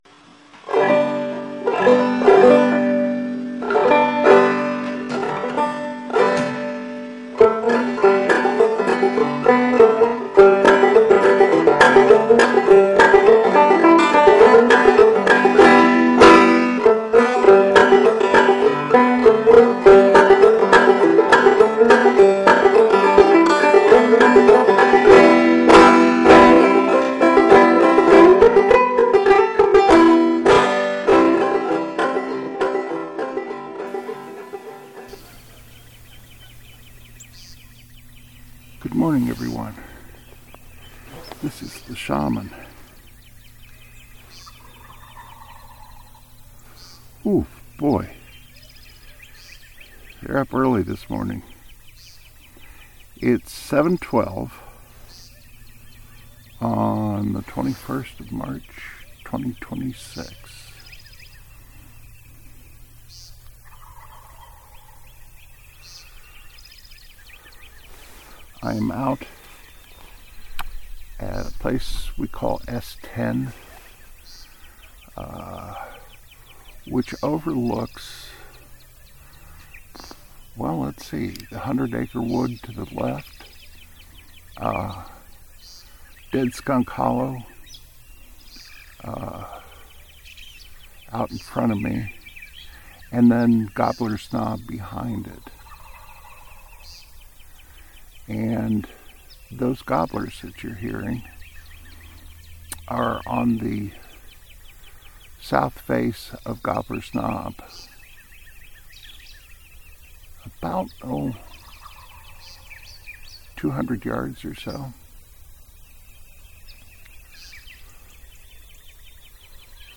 We have a blind overlooking Dead Skunk Hollow and Gobbler’s Knob.
It makes a great listening post for turkey hunting. I went out with the recording gear and captured a bunch of gobblers waking up on the south face of Gobbler’s knob.